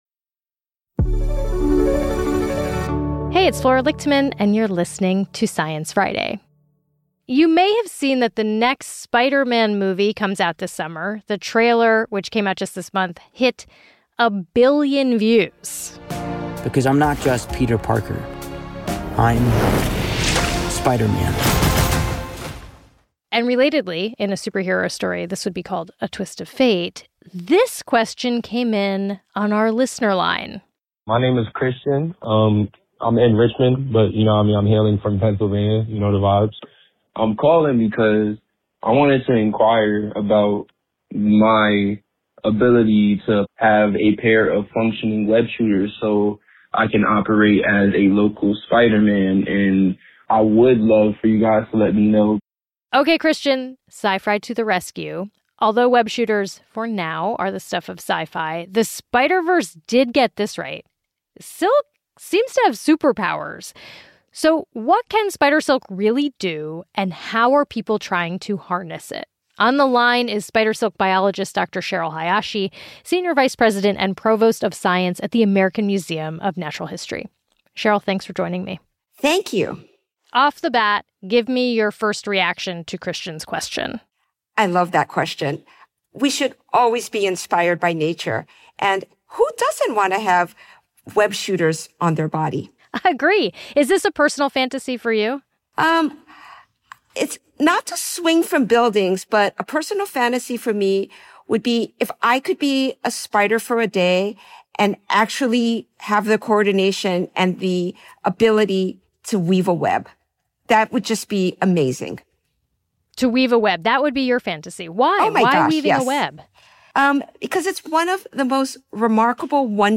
A listener recently called in asking how they might get a pair of functioning web shooters so they could operate as a local Spider-Man.